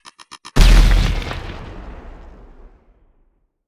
Grenade3.wav